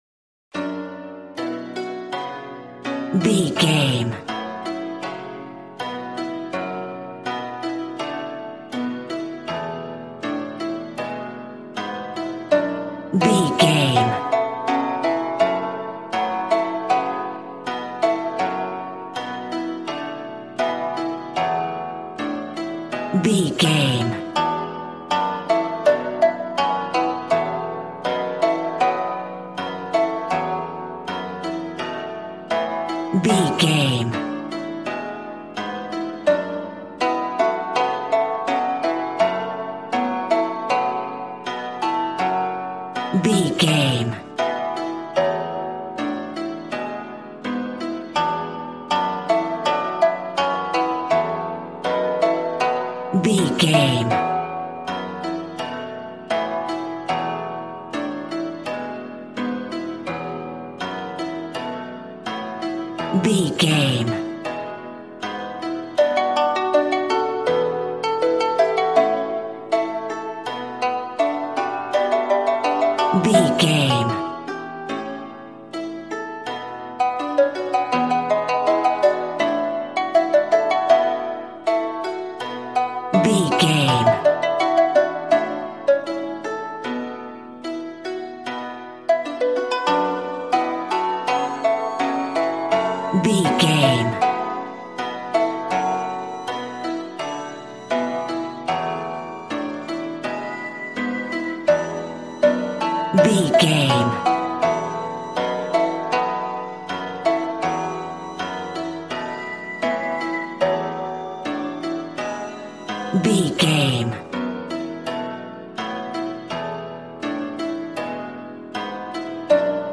Ionian/Major
smooth
conga
drums